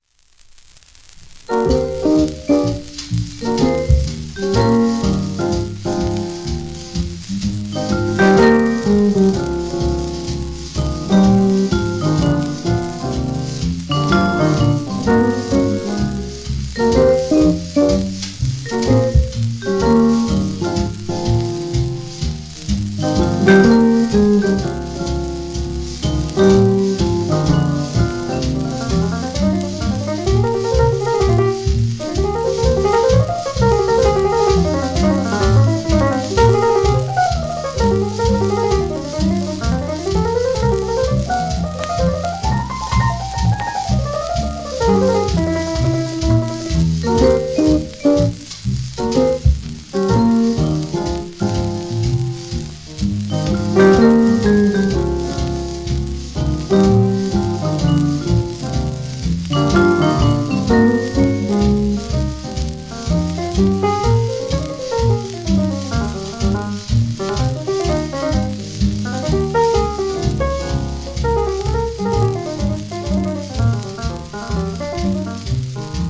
w/Vibes
Guitar
Drums
Bass
1949年録音